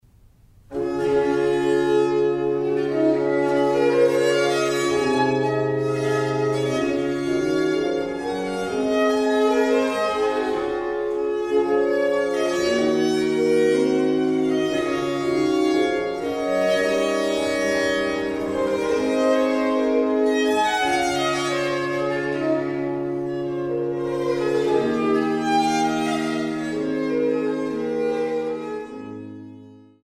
Barockgeige
Trost-Orgel Waltershausen